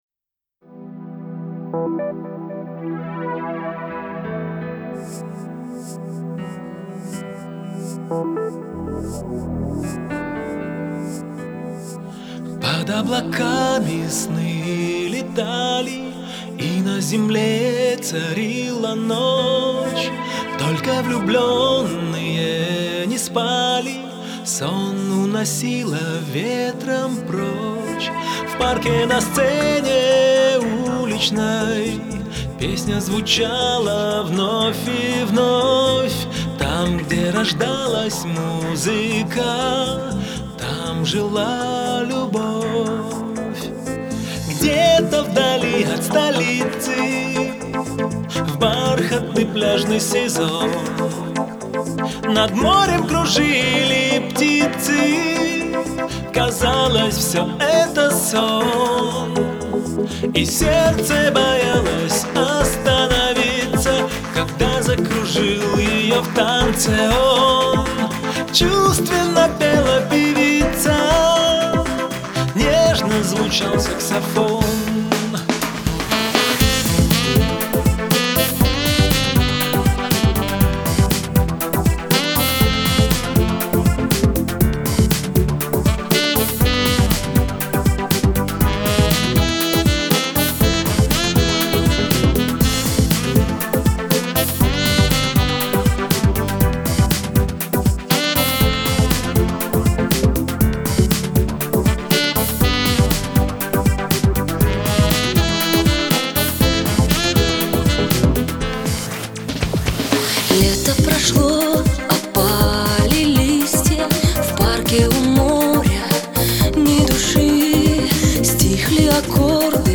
это яркая композиция в жанре поп с элементами джаза